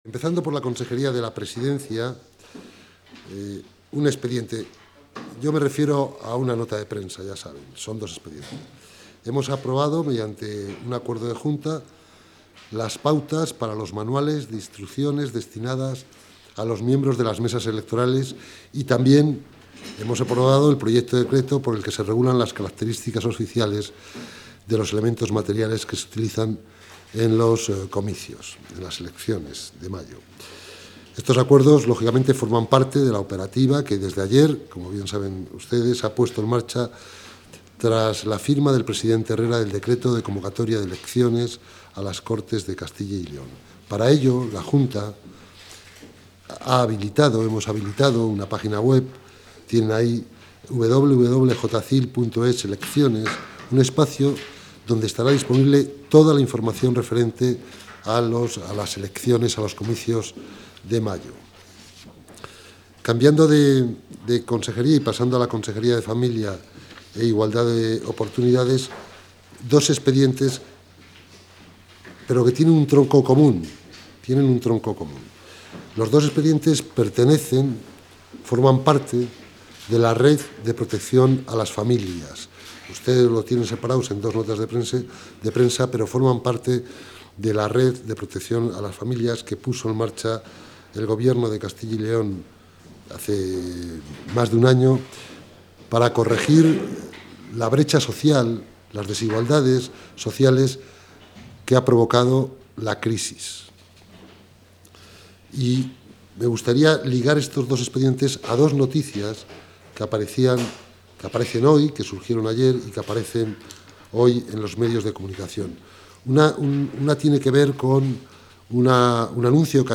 Consejo de Gobierno del 1 de abril de 2015.